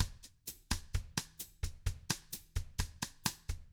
129BOSSAF1-L.wav